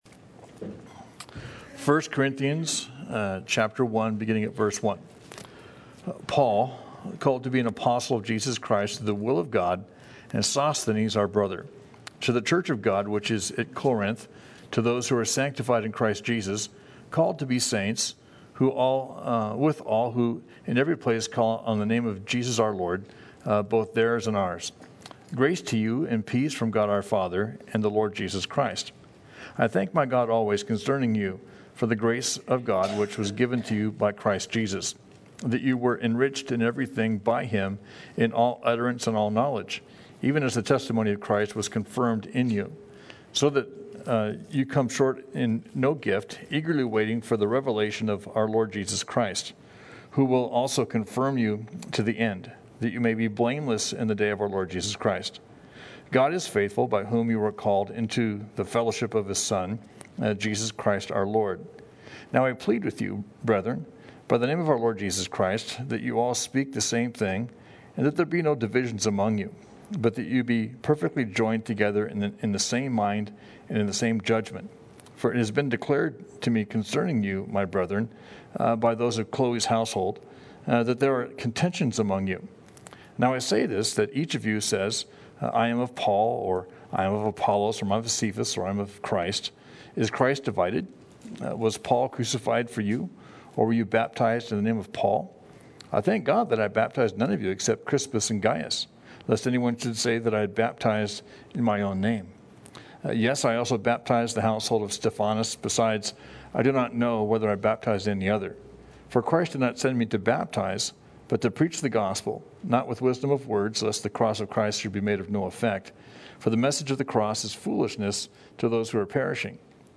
Thank’s for checking out our study in 1st Corinthians.